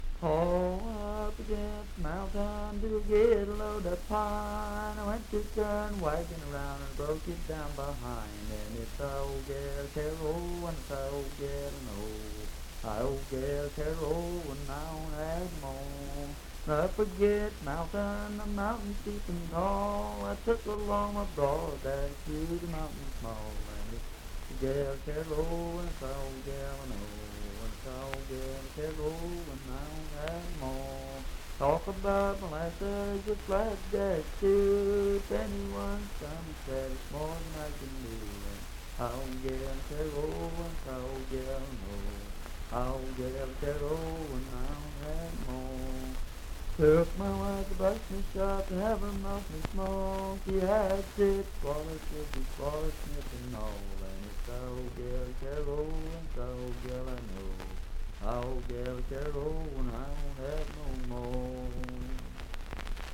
Unaccompanied vocal music
Voice (sung)
Pendleton County (W. Va.), Franklin (Pendleton County, W. Va.)